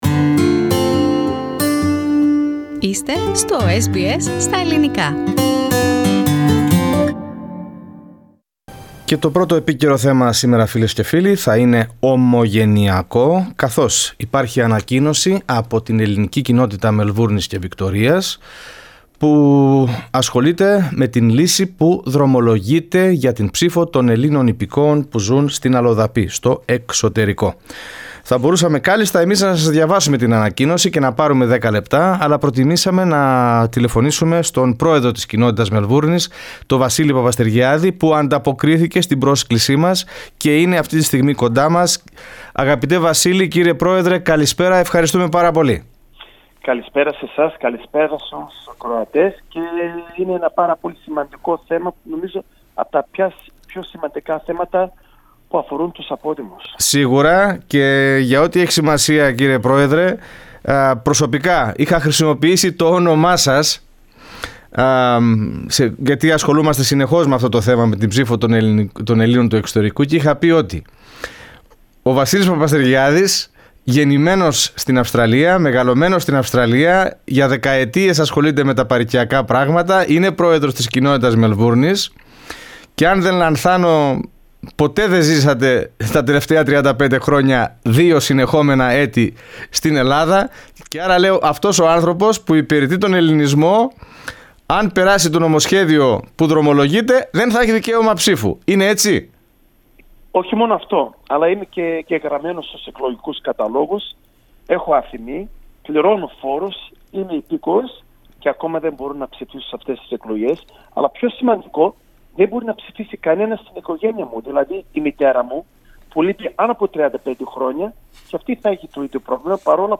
Σε συνέντευξή